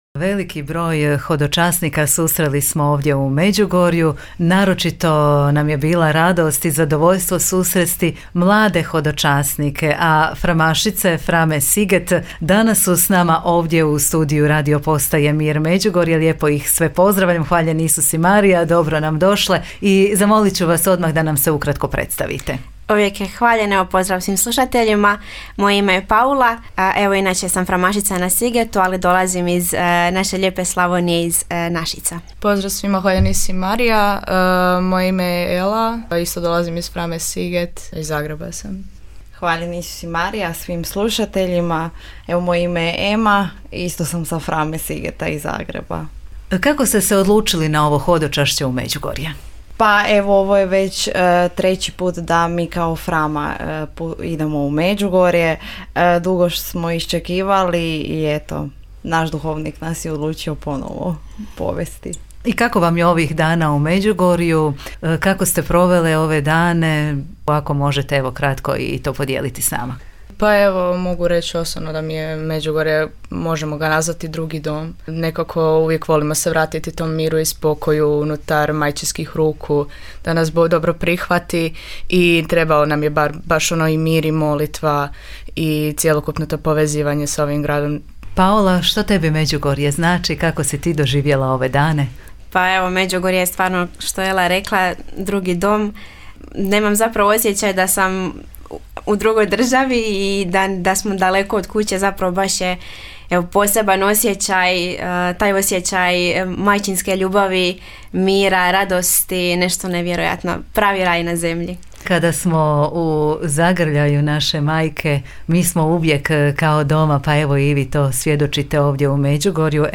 Cijeli razgovor, emitiran u Popodnevnom mozaiku Radiopostaje Mir Međugorje, poslušajte u audiozapisu.